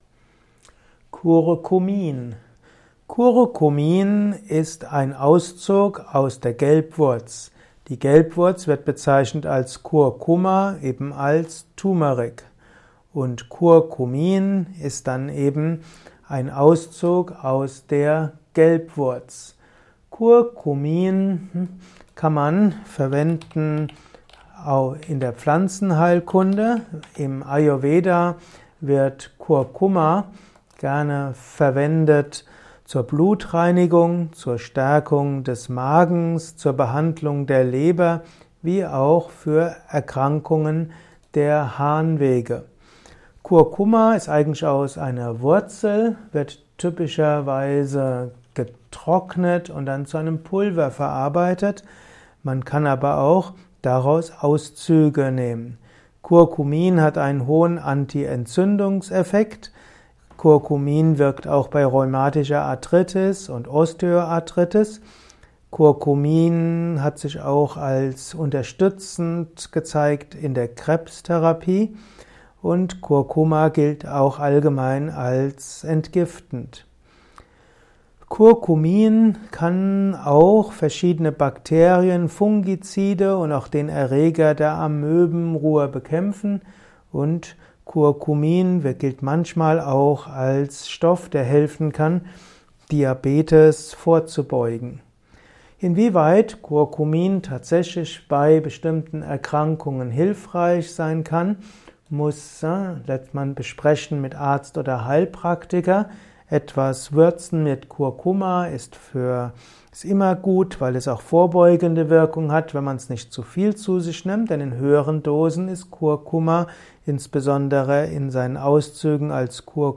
Kurzvortrag